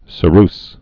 (sə-rs, sîrs)